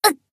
贡献 ） 分类:蔚蓝档案语音 协议:Copyright 您不可以覆盖此文件。
BA_V_Chinatsu_Hotspring_Battle_Damage_1.ogg